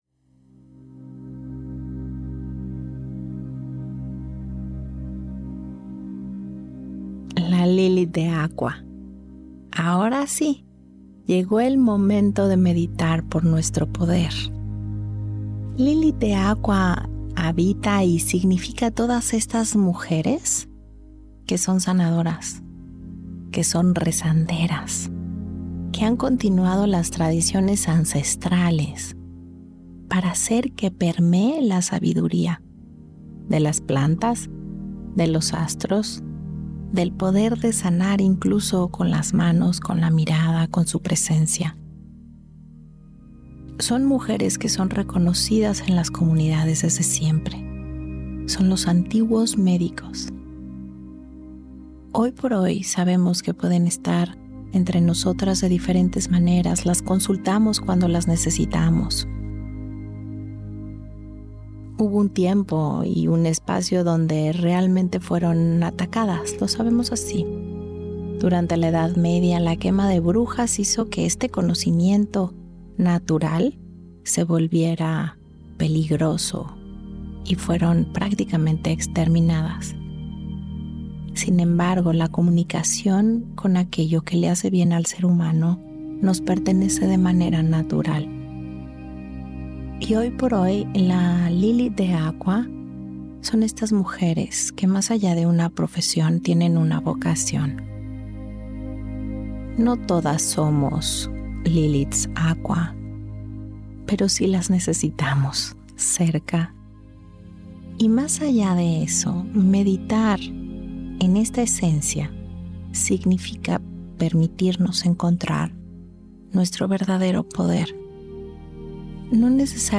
Esta meditación nos invita a reconectar con nuestro verdadero poder.